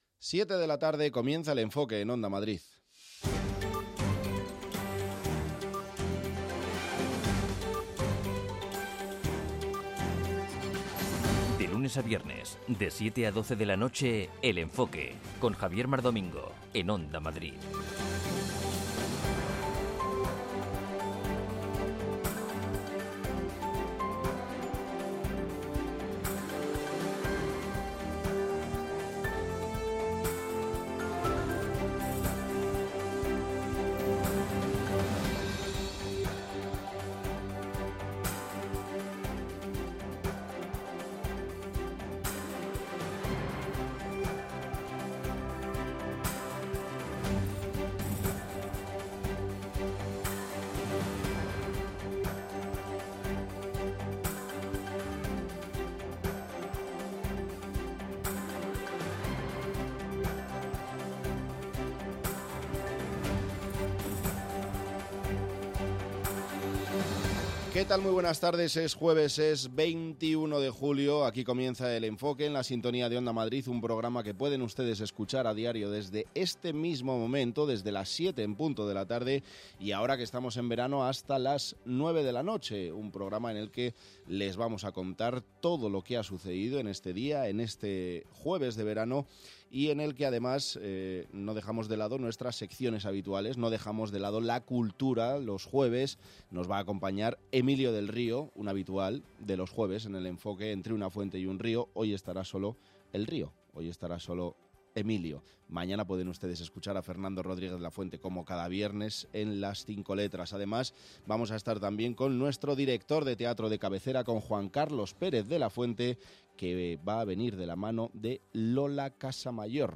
Cada tarde/noche a partir de las 19:00, Félix Madero da una vuelta a la actualidad, para contarte lo que ha pasado desde todos los puntos de vista. La información reposada, el análisis, y las voces del día constituyen el eje central de este programa, con la vista puesta en lo que pasará al día siguiente.